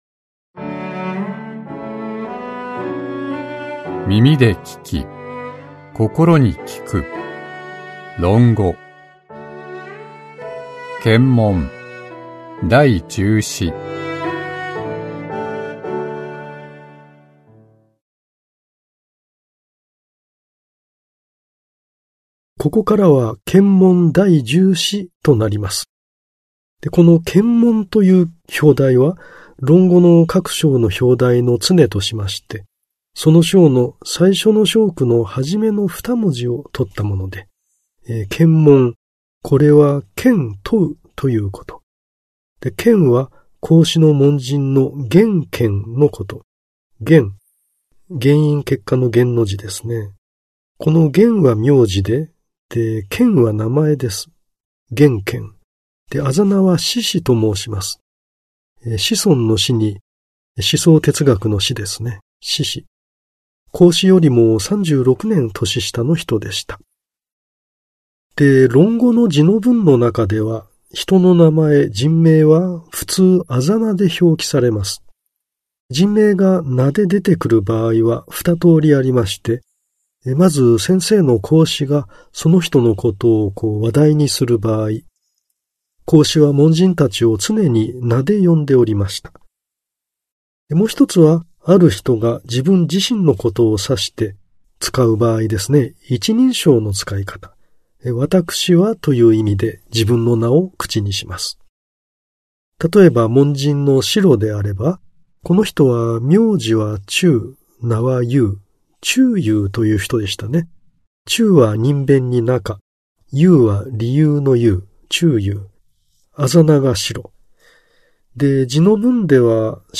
[オーディオブック] 耳で聴き 心に効く 論語〈憲問第十四〉